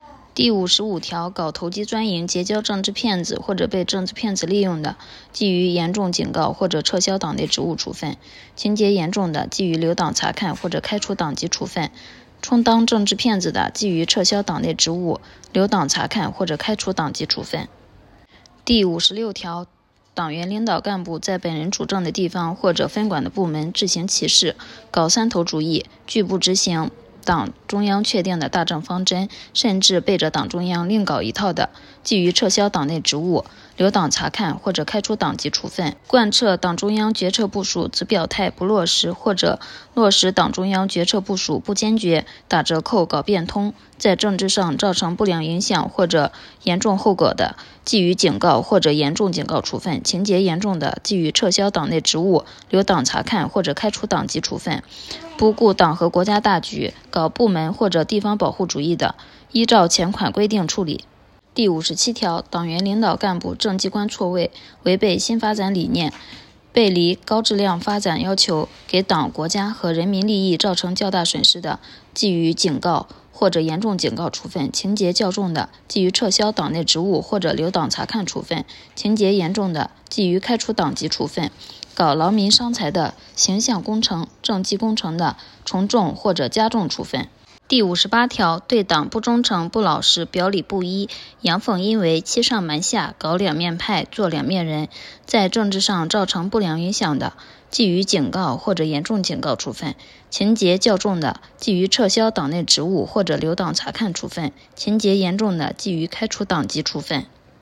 菏泽医专附属医院开展党纪学习教育有声诵读活动 （第八期）